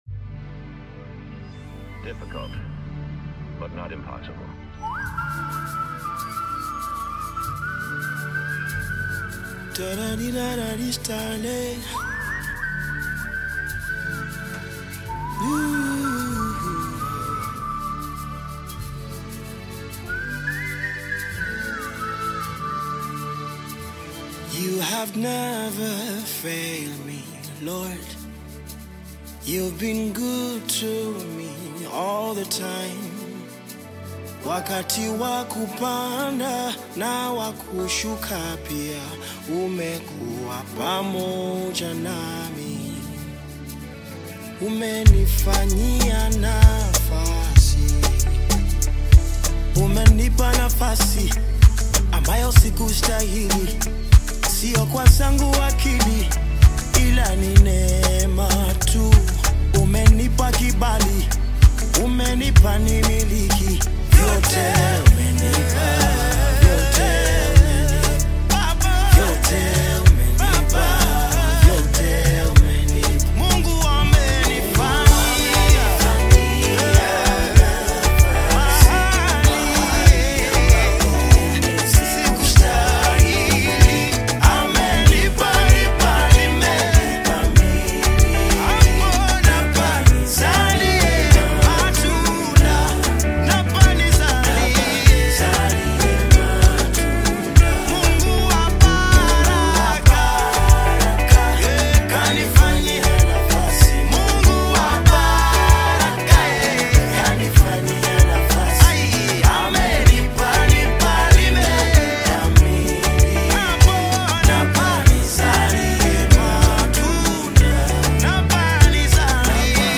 Gospel music
Tanzanian gospel artist, singer, and songwriter